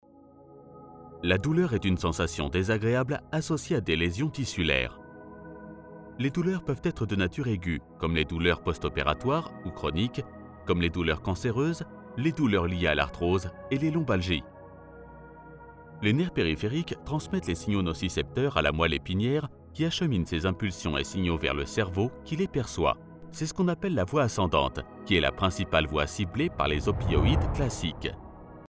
Contrate dubladores de narração médica para o seu projeto
Articular
Crível
Calma